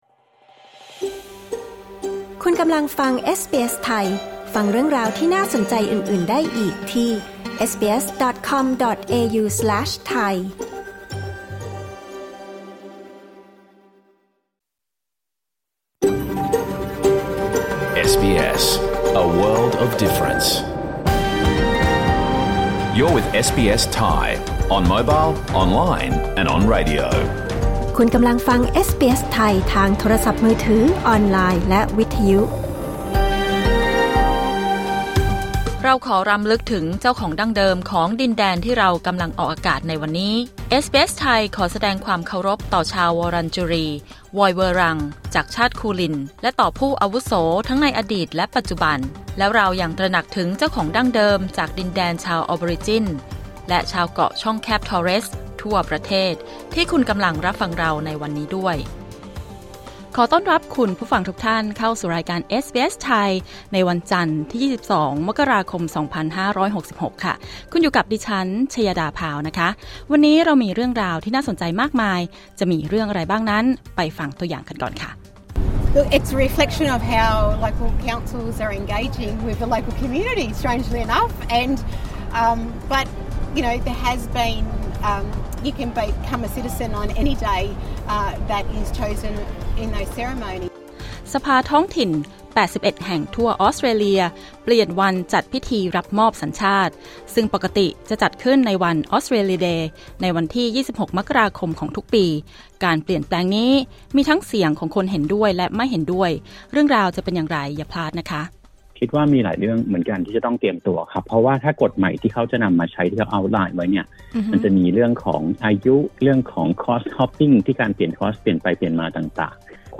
รายการสด 22 มกราคม 2567